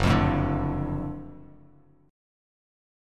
F#mM7#5 chord